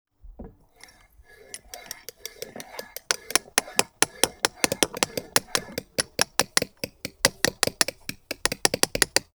TOOL_Chisel_Sequence_05_mono.wav